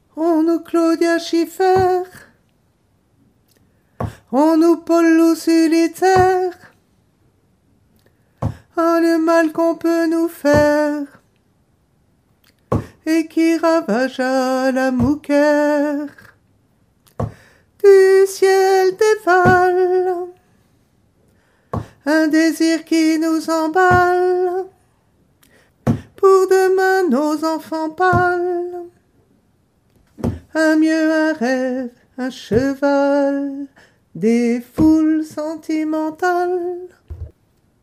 Couplet 3 basses 1